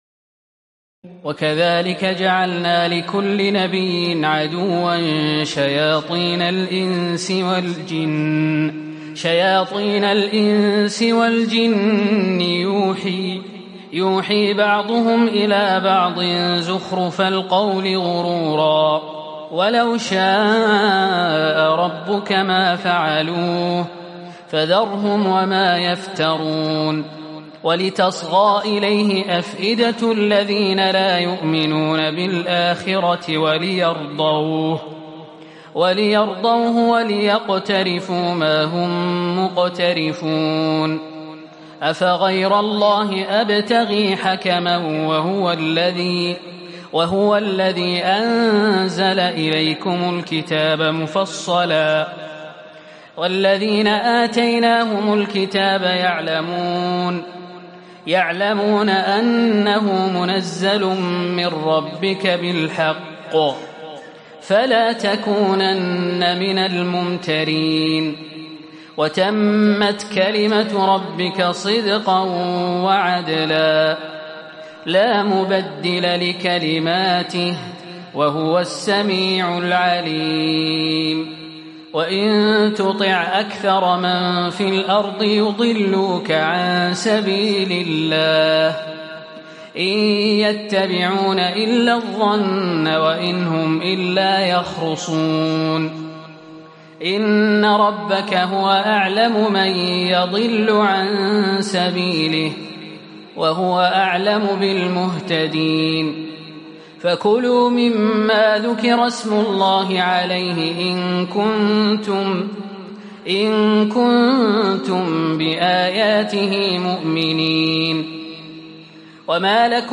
تراويح الليلة الثامنة رمضان 1438هـ من سورة الأنعام (112-165) Taraweeh 8 st night Ramadan 1438H from Surah Al-An’aam > تراويح الحرم النبوي عام 1438 🕌 > التراويح - تلاوات الحرمين